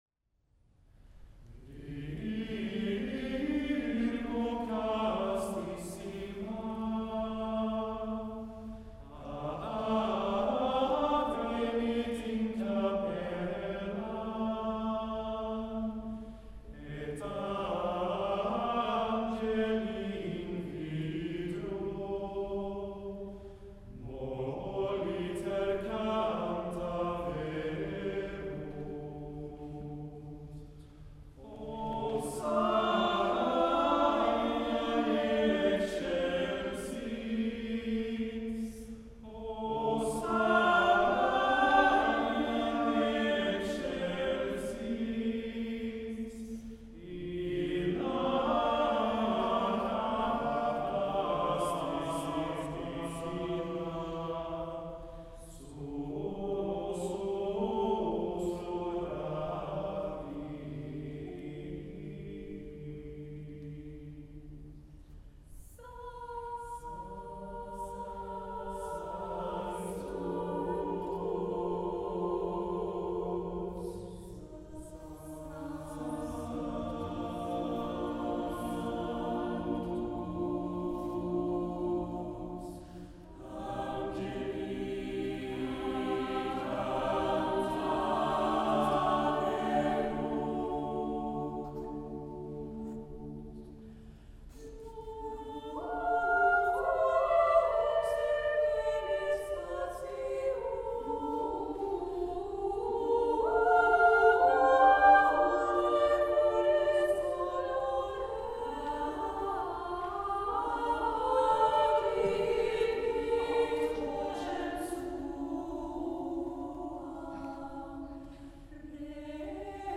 I söndags var det dags för gudstjänst igen.
Jag var där av en anledning; för att sjunga med den fantastiska Haga Motettkör (som jag fortfarande inte riktigt fattar att jag kom med i när jag provsjöng förra våren, för den är så himla bra).
Jag ska inte gnälla om kyrkan och vad jag tycker om den, istället vill jag dela med mig av ett stycke vi sjöng; Sainte-Chapelle av Eric Whitacre. Så fantastiskt vackert.